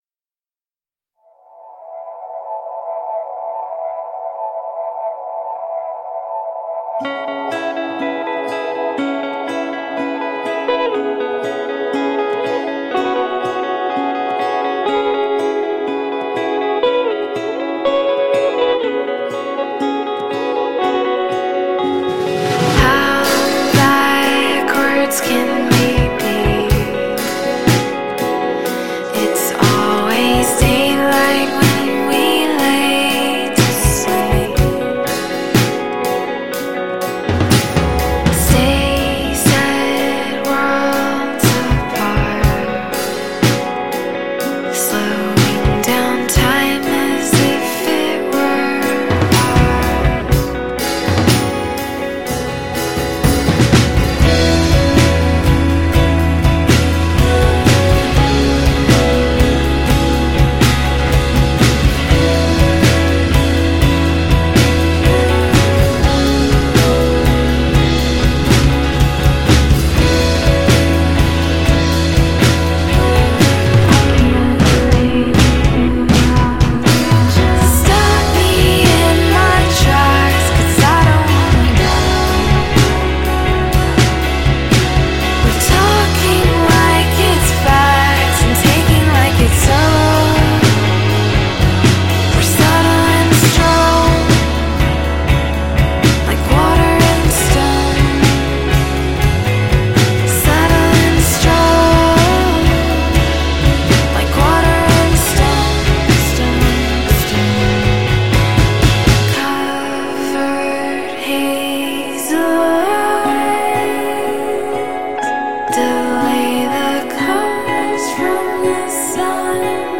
Orlando trio